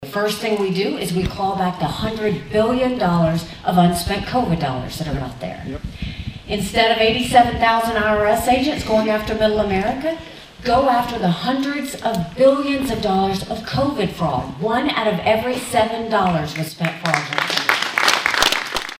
Republican Presidential Candidate Nikki Haley Makes Campaign Stop in Atlantic